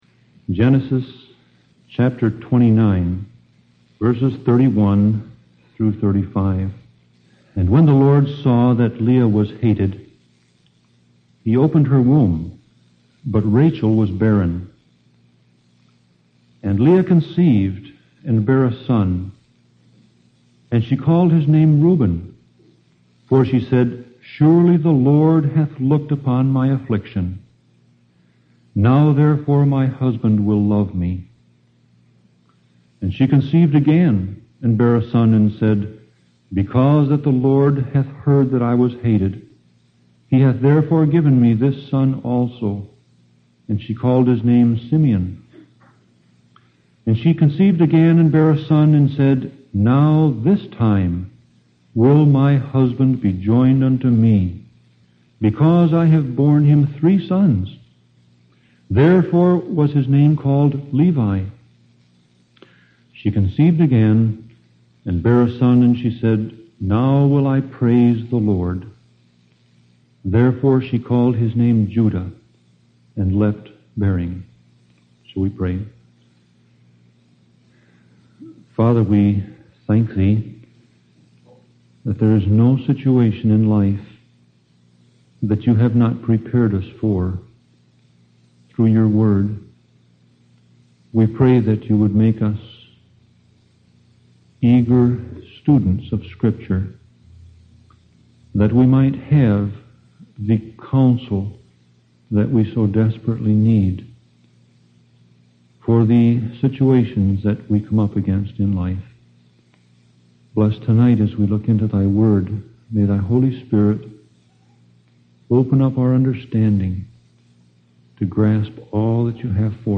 Sermon Audio Passage: Genesis 29:31-35 Service Type